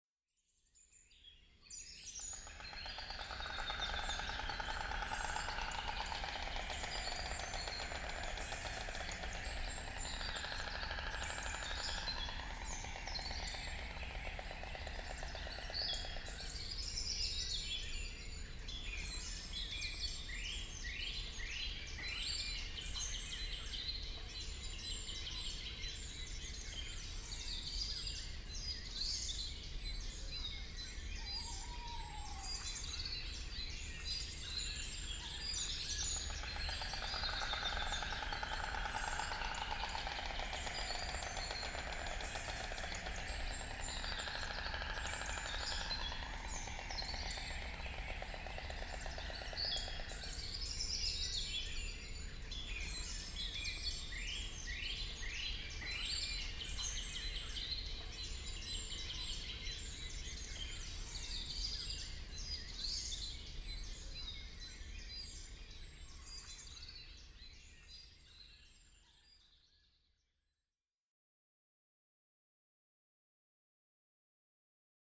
valge-toonekurg.mp3